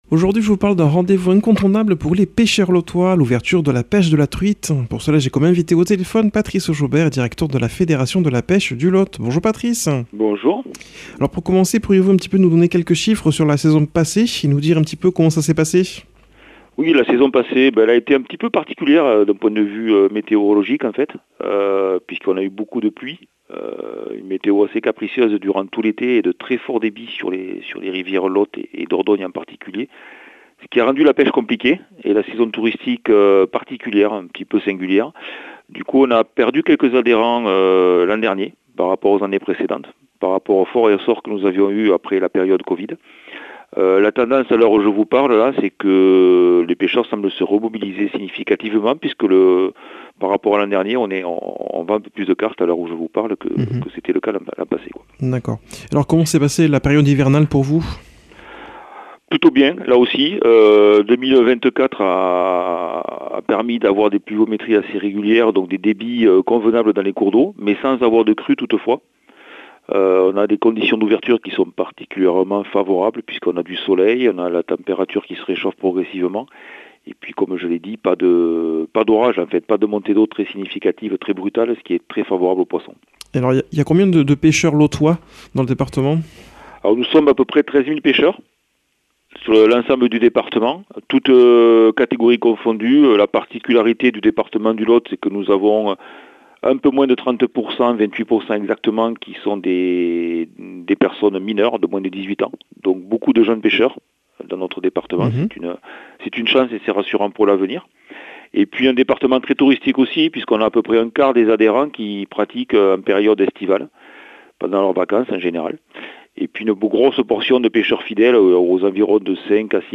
par téléphone